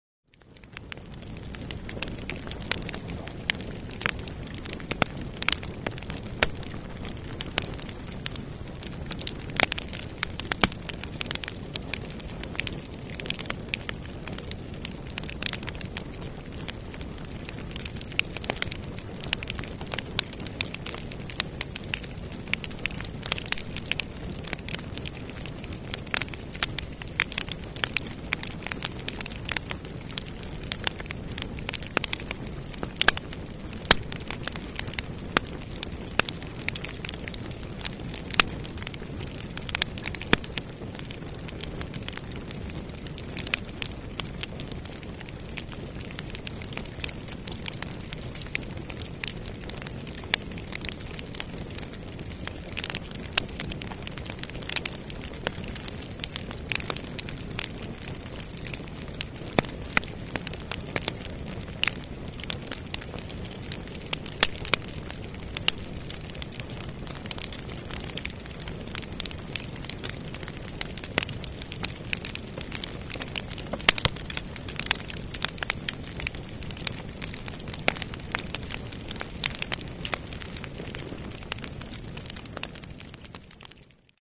Подборка включает разные варианты: от спокойного потрескивания до яркого горения.
Звуковой эффект Шум горящего костра в поле 2